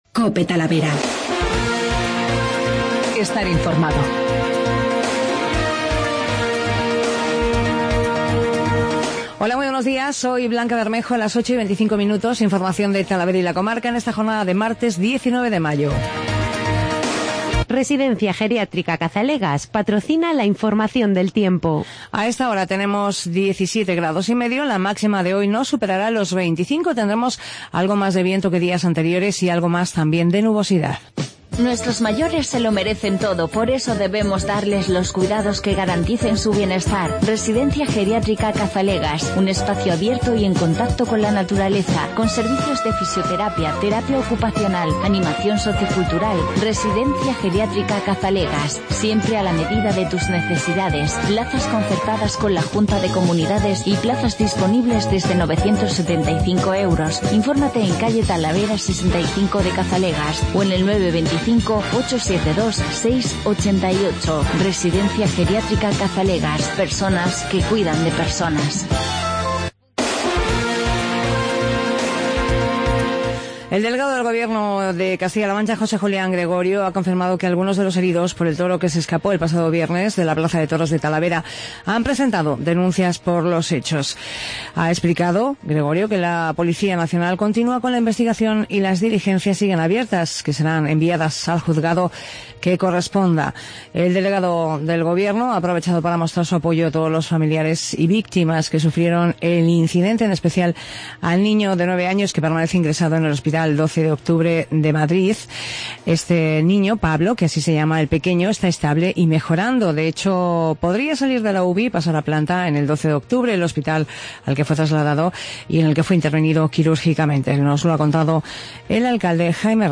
Informativo Matinal